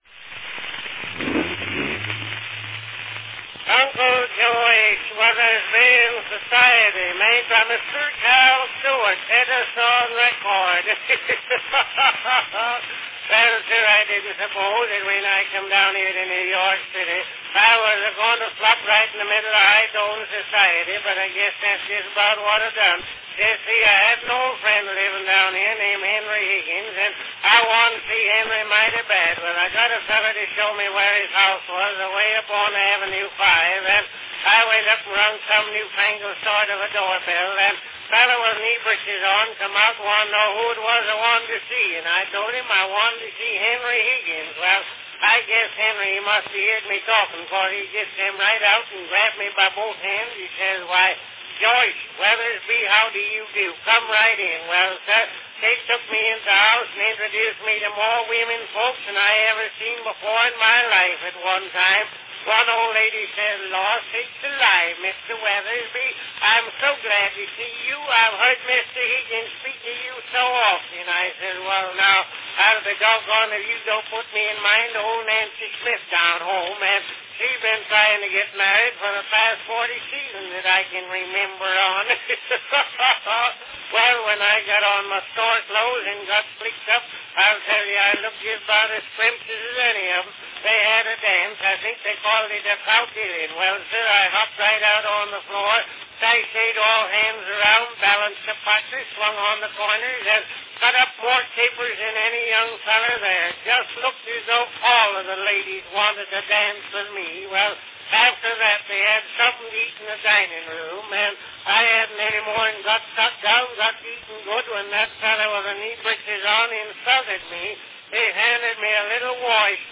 From 1899, a humorous monolog featuring Uncle Josh in the big city mistakenly stepping on the wrong social rung in Uncle Josh in Society, by Cal Stewart.
Company Edison's National Phonograph Company
Category Funny talk
Performed by Cal Stewart
Announcement "Uncle Josh Weathersby in Society, made by Mr. Cal Stewart.  Edison record."
The odd sounds heard at the beginning of the recording are artifacts from the one-at-a-time record duplicating process from the master cylinder.